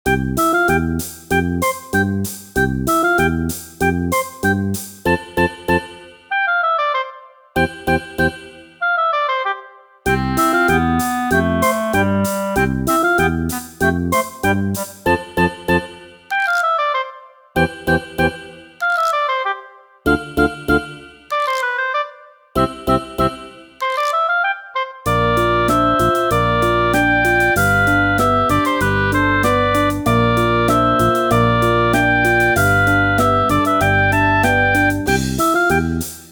ogg(R) 日常系 明るい ポップ
日常系まったりポップ曲。